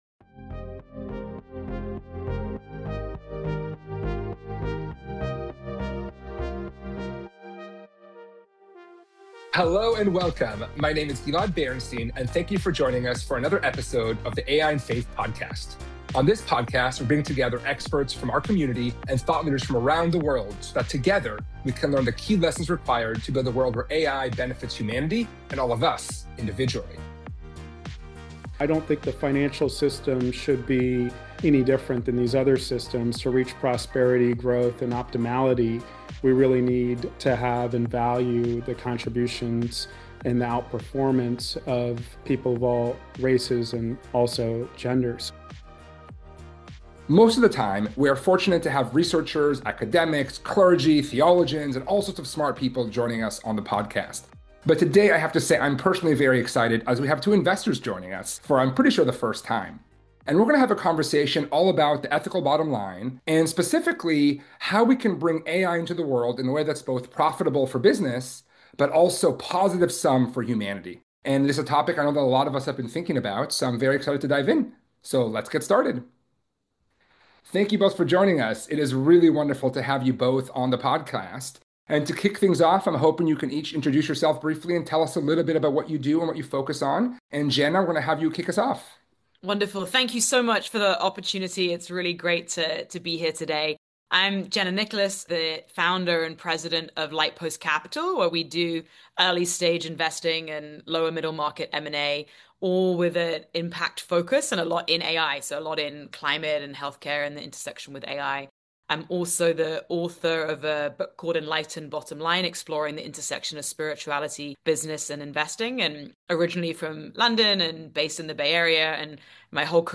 In this conversation, we were joined by two Silicon Valley investors, who focus on investing in artificial intelligence in a way that is positive sum for society, what they referred to as the enlightened bottom line. In our discussion, they explained this term and how they draw on their faith traditions to find investments and business opportunities with great returns, positive impact on humanity, and ones that support their religious and spiritual desires. We concluded the conversation with a deep dive into the Baháʼí faith and the many ways AI is utilized within that community.